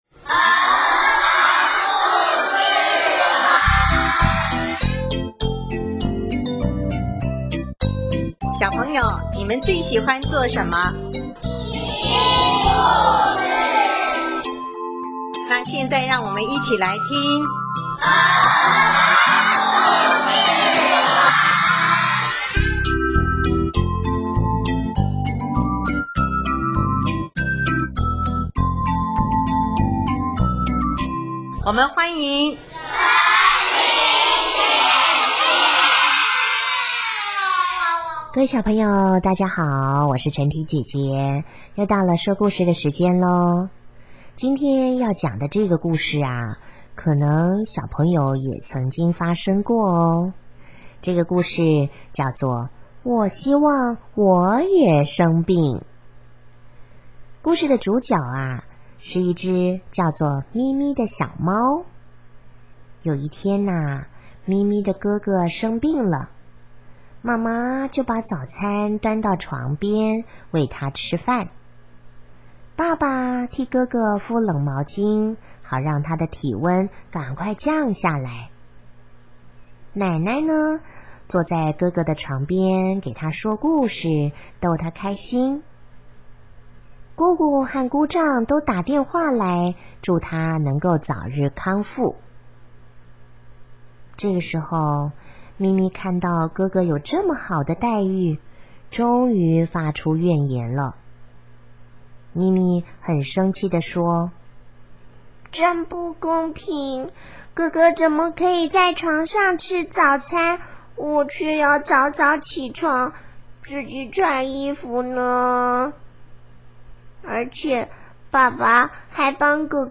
【儿童故事】|我希望我也生病